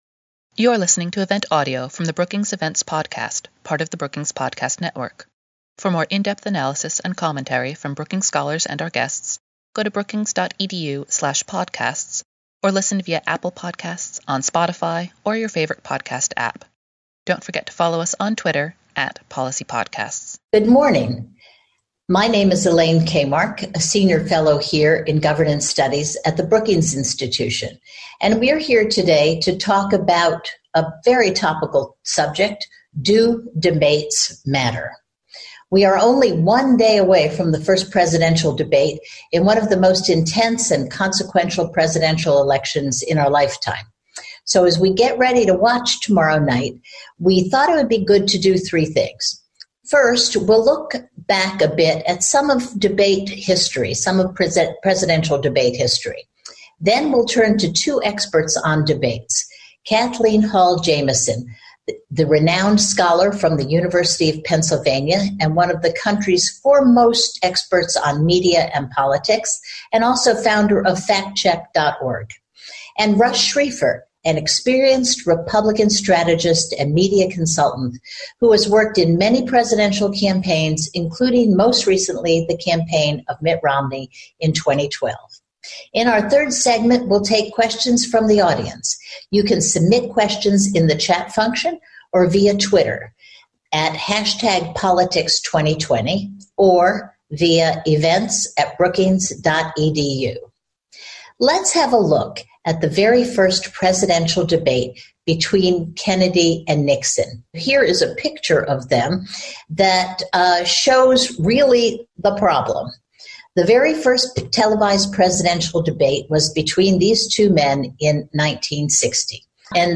On September 28, Brookings hosted a webinar with a media expert and experts who have helped advise past presidential candidates to explore the role of debates in previous elections – including by highlighting some of the most famous moments from previous presidential match-ups.